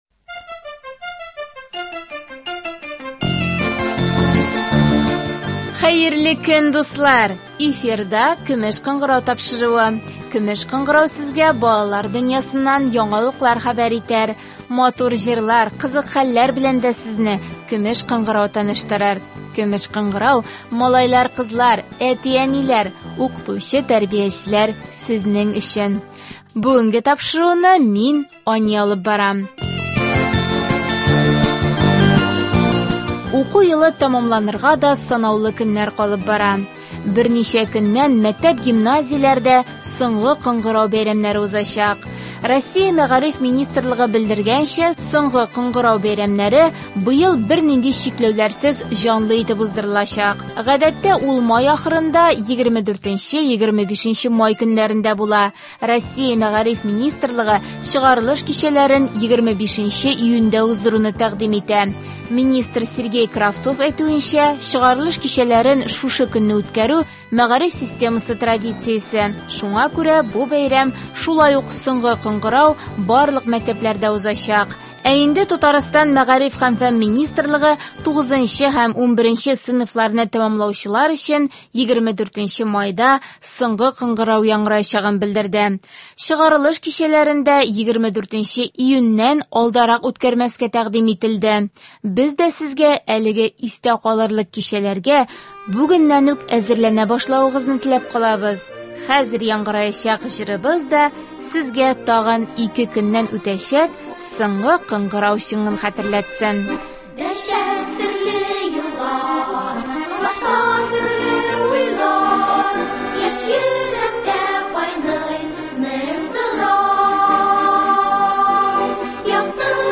Бүген дә аларның кайберләре безнең студиябез кунаклары.